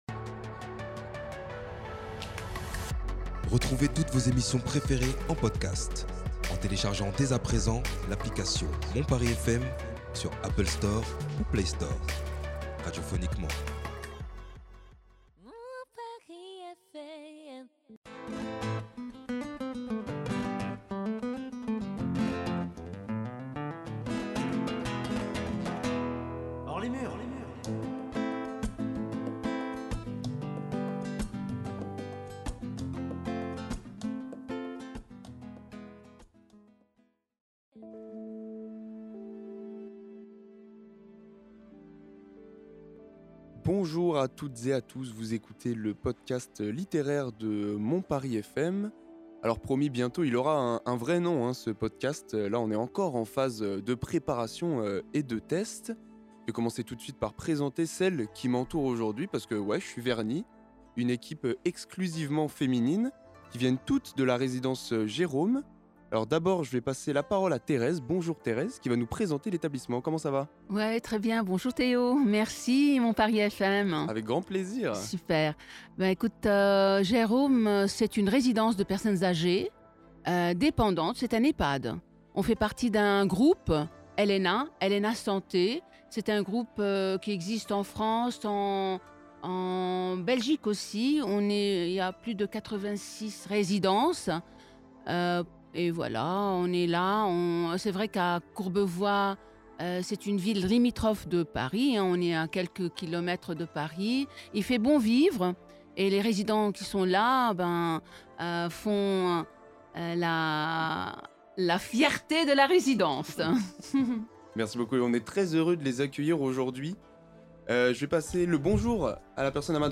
Les résidentes de GER'Home à Courbevoie nous partagent leurs lectures du moment et nous recitent des Haikus à l'antenne.